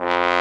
Index of /90_sSampleCDs/Roland LCDP12 Solo Brass/BRS_Trombone/BRS_Tenor Bone 4
BRS BONE P0E.wav